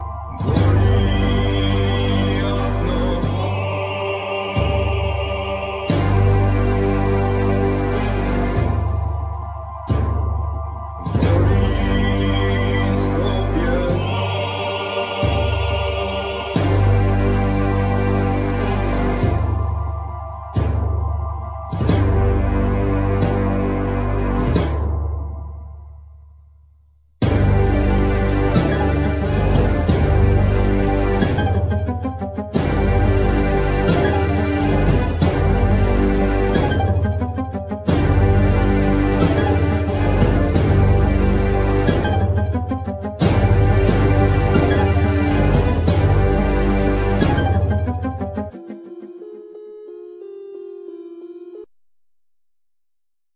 Vocal,Bagpipes,Flute
Guitar,Mandolin,Flute,Back vocals
Drums,Percussions,Keyboards,Back vocal